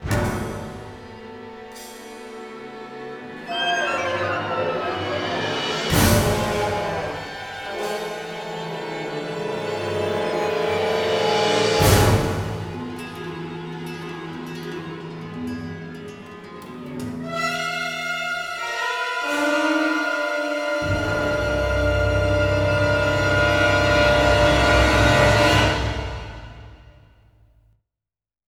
Slightly re arranged compared to the JP version, also longer